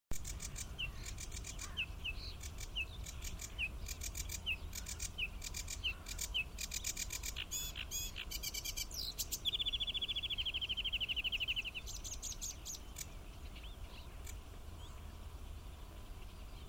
барсучок, Acrocephalus schoenobaenus
Ziņotāja saglabāts vietas nosaukumsGrāvis
СтатусПоёт